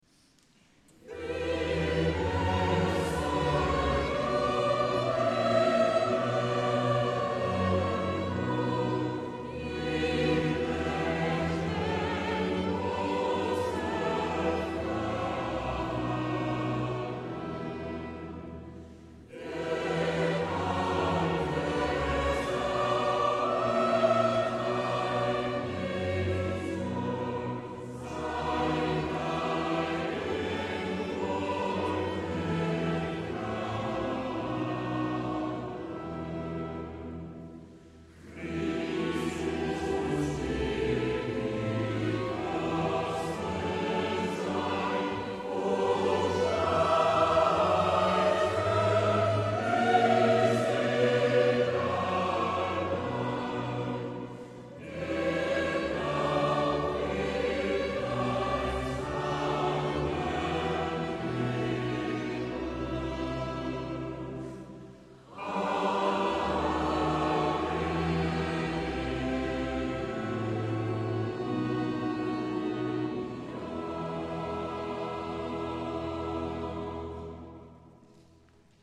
donnée à Porrentruy le 23 juin 2012
Choral final chanté avec le public